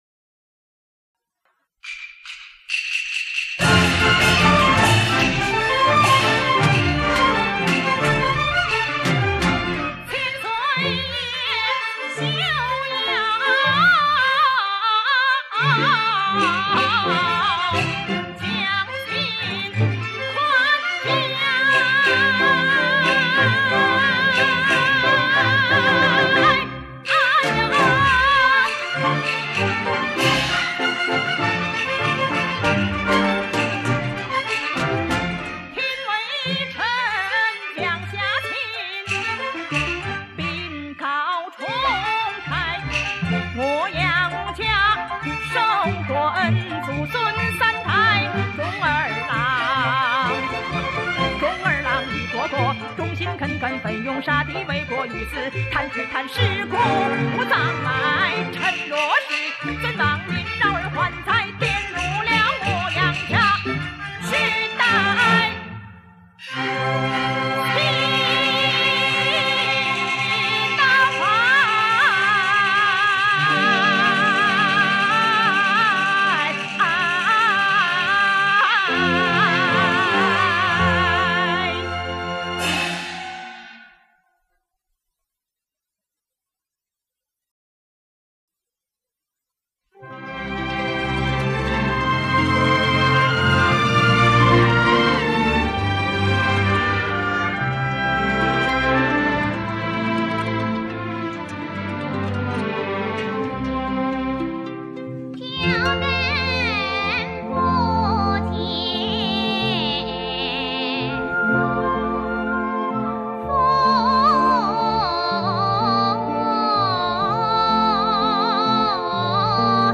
电子乐器加传统乐器的伴奏 对戏曲来说是一次大胆的尝试